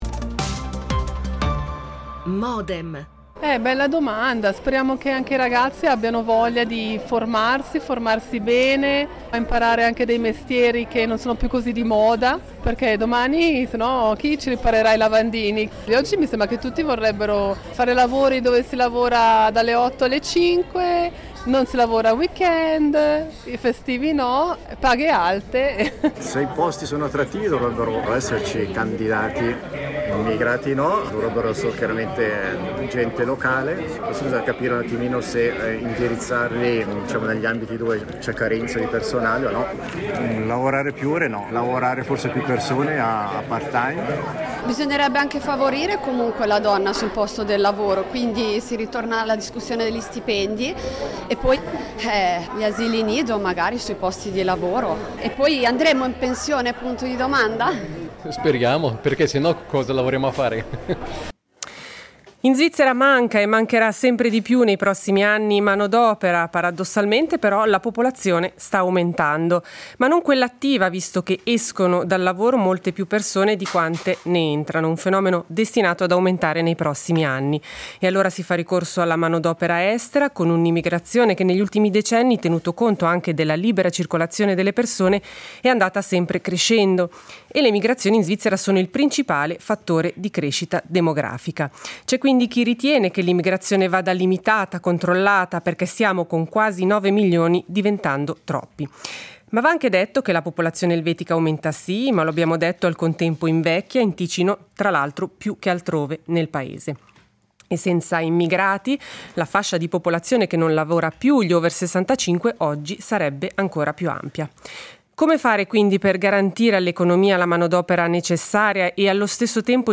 L'attualità approfondita, in diretta, tutte le mattine, da lunedì a venerdì